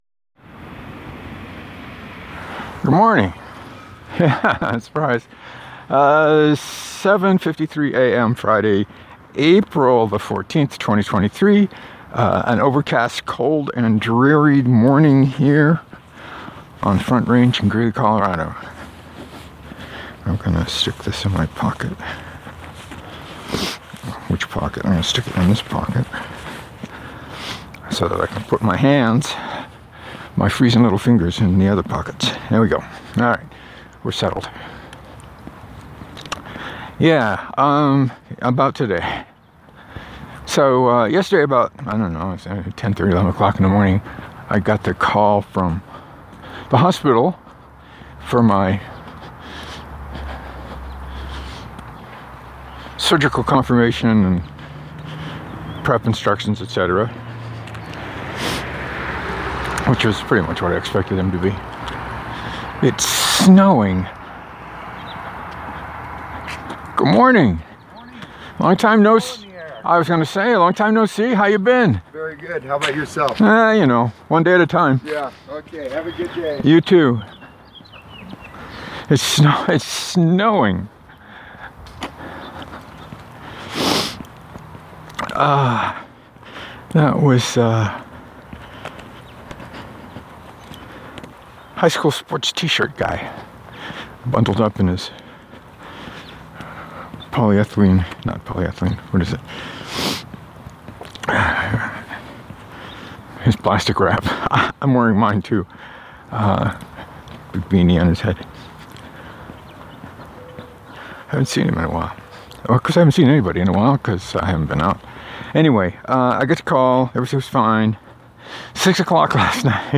So I walked in the snow. To be fair, it didn’t start snowing until I was about halfway around the loop and only spit a bit. I talked a lot about bread.